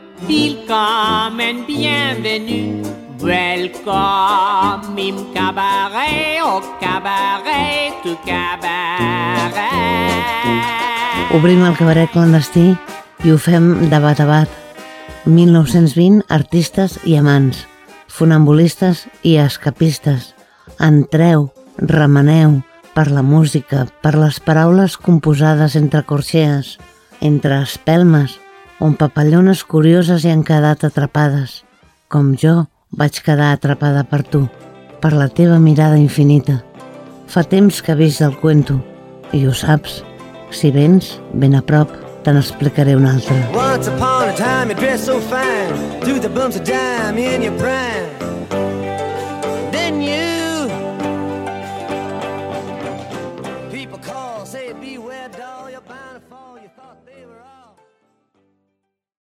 Presentació d'un tema musical
Entreteniment